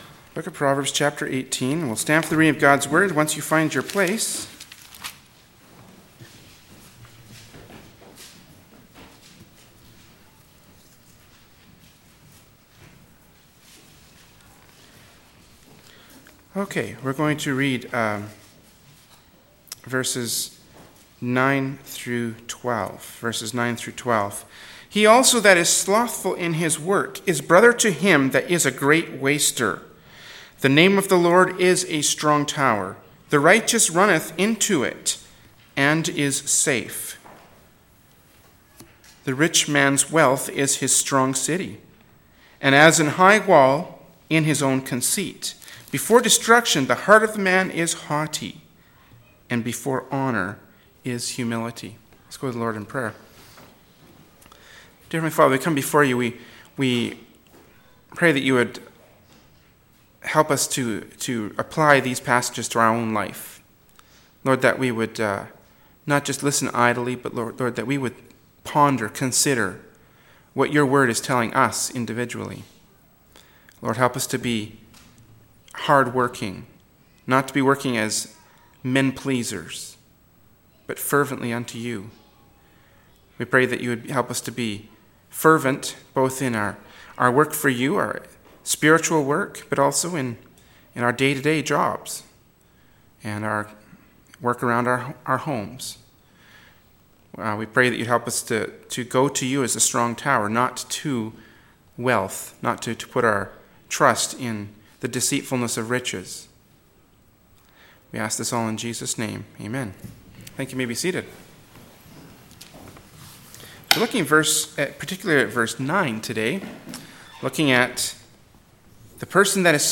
“Proverbs 18:9-12” from Sunday School Service by Berean Baptist Church.